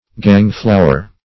Search Result for " gang-flower" : The Collaborative International Dictionary of English v.0.48: Gang-flower \Gang"-flow`er\, n. (Bot.)